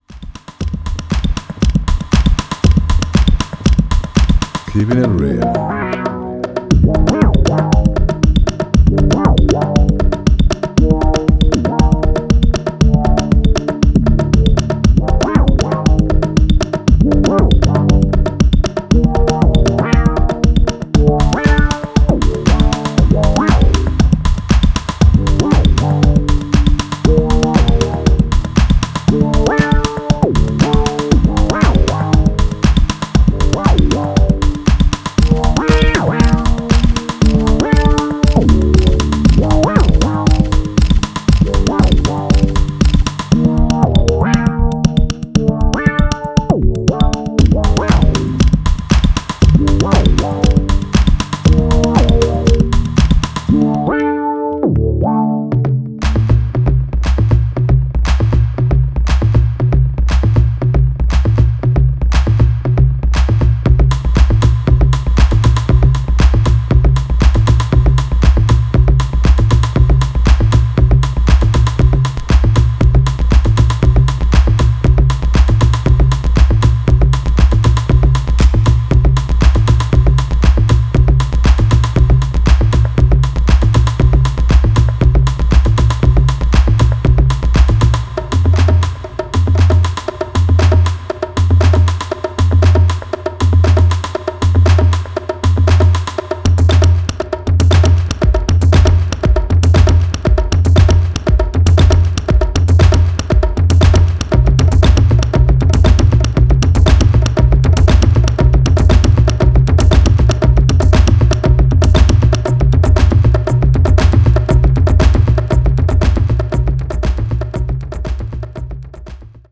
アブストラクトに揺らめくリードシンセとドライなパーカッションが酩酊を誘う